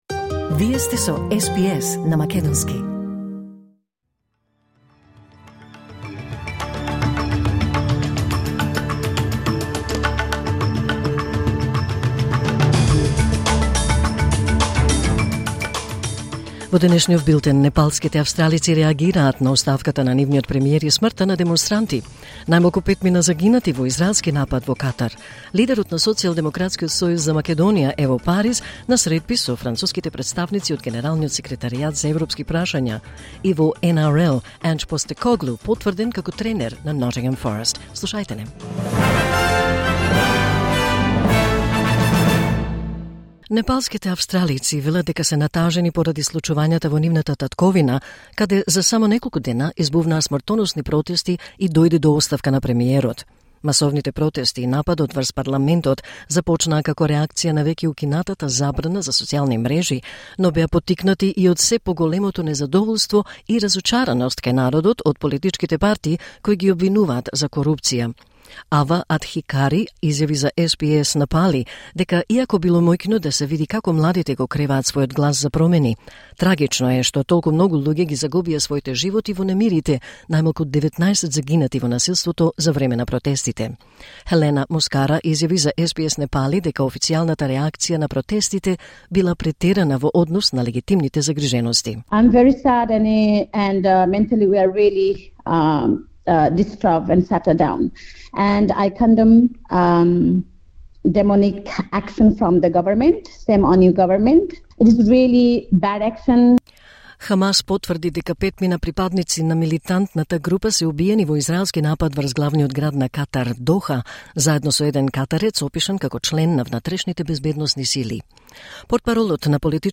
Вести на СБС на македонски 10 септември 2025